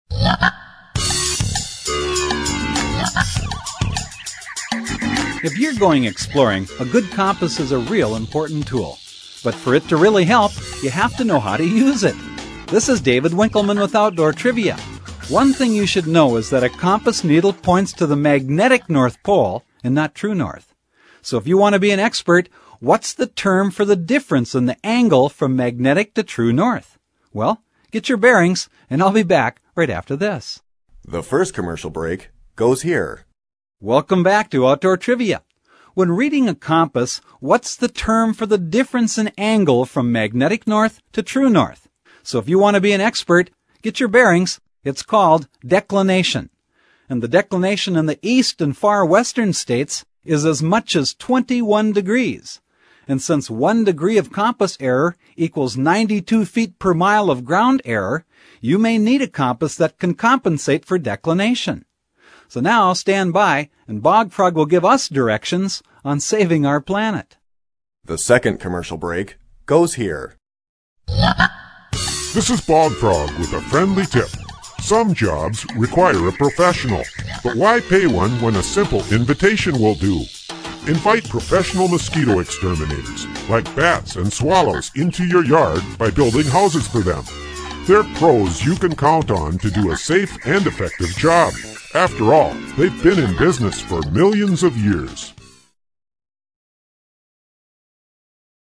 In fact, the question and answer trivia format of this program remains for many people, a most enjoyable, yet practical method of learning.
Programs are 2 1/2 minutes long, including commercial time. Bog Frog’s Tips conclude each program.
Bog Frog's voice is distinctive and memorable, while his messages remain positive and practical, giving consumers a meaningful symbol to remember.